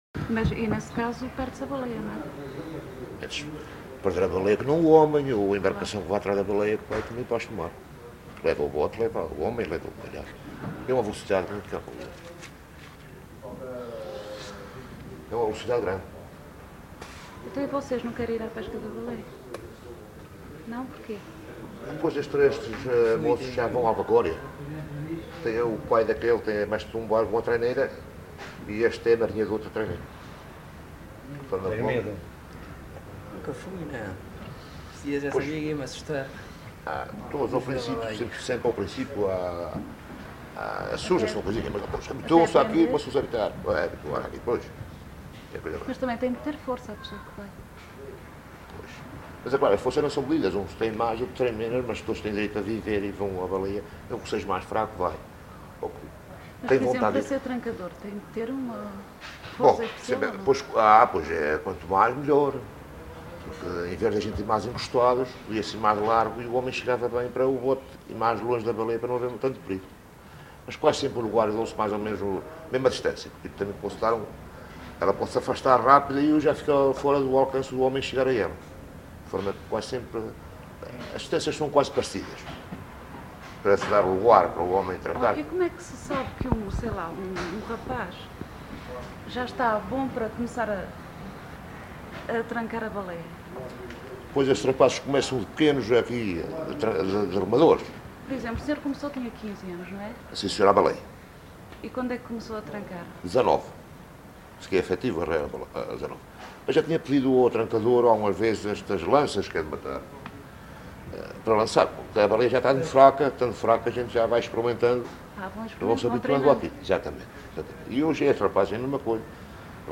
LocalidadeRibeiras (Lages do Pico, Horta)